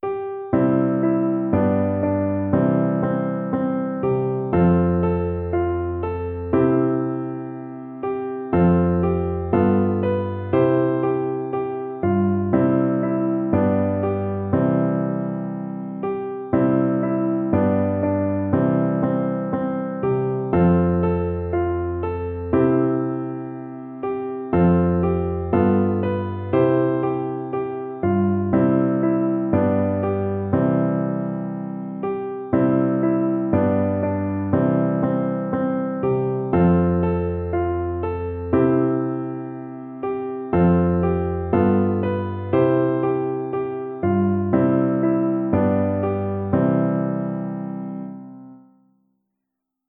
Etwas voluminöser als Klavierfassung